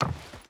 Wood Run 3.ogg